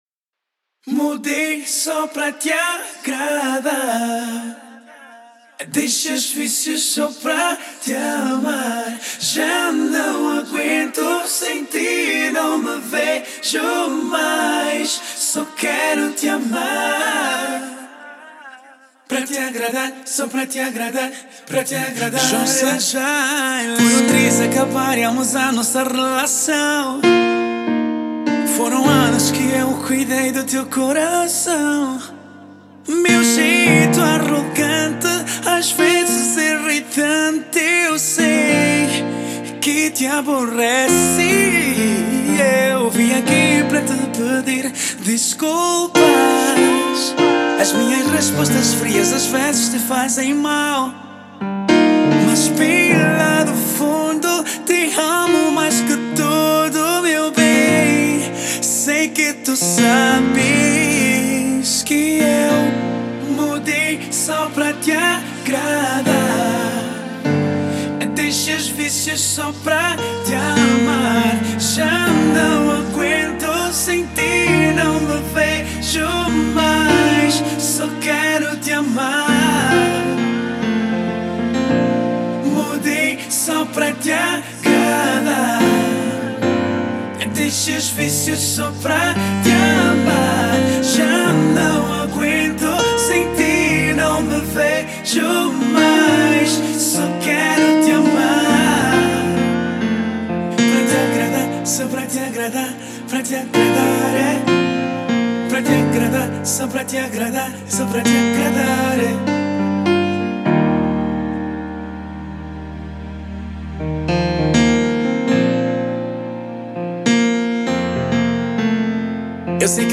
Gênero: Balada Produção e vozes